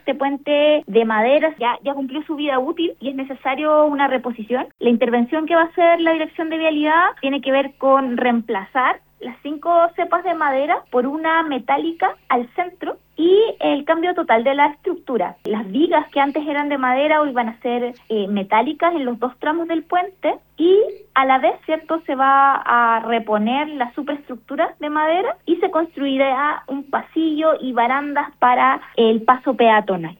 En conversación con Radio Bío Bío, la seremi de Obras Públicas, Francisca Vergara, mencionó que las obras contemplan el cambio total de la estructura.